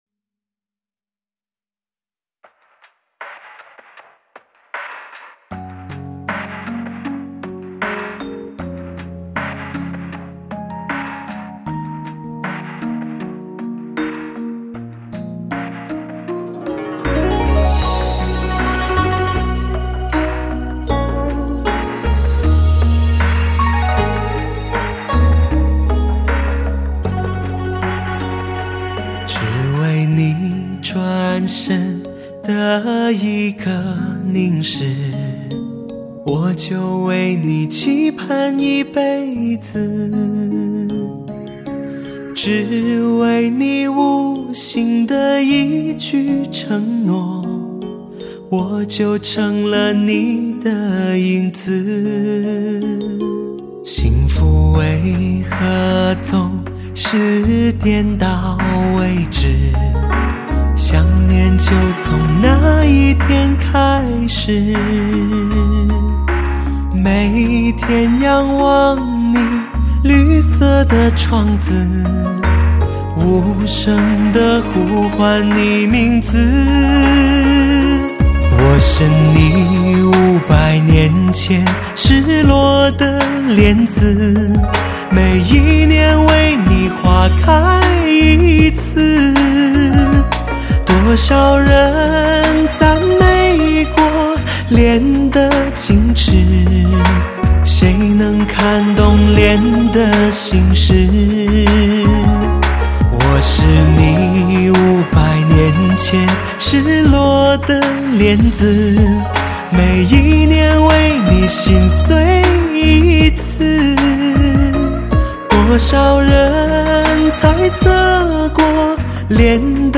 睿智儒雅而又温和，东方诗人般沉静优雅的男子，
他那温润优雅的嗓音，气量充沛，音域广阔，纯熟温润的歌声